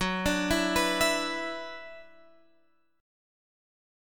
F#7sus4#5 chord